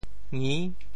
潮语发音
ngi6 uan1 gung6